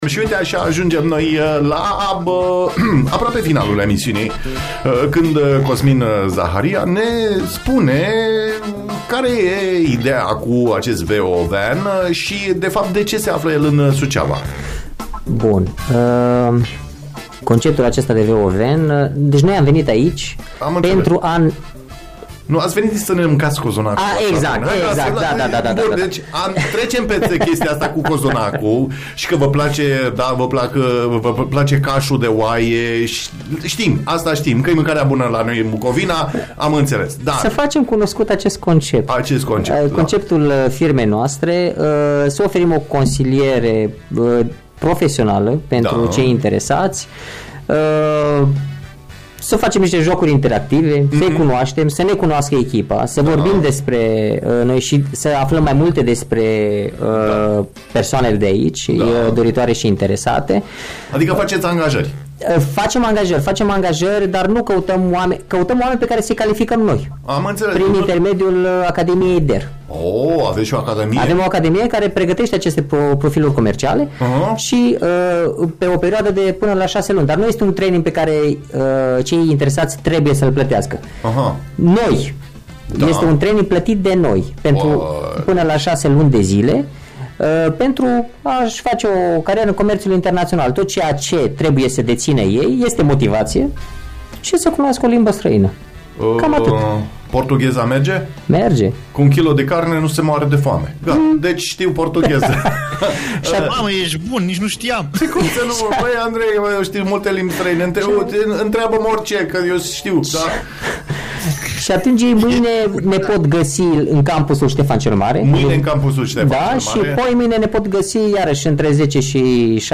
All the hits One station